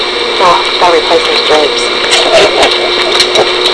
EVP's
The laughing was not one of us. There were no males with us this investigation.